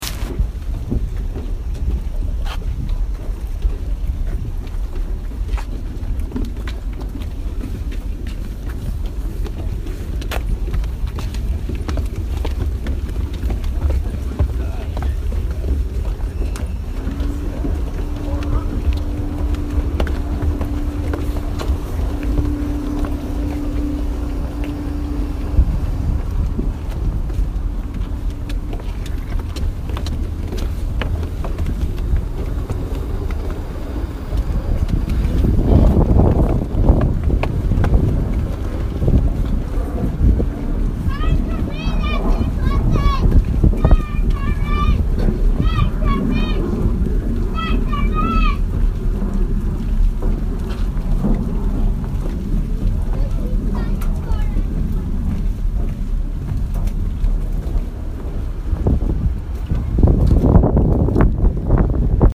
Walking
Walking.mp3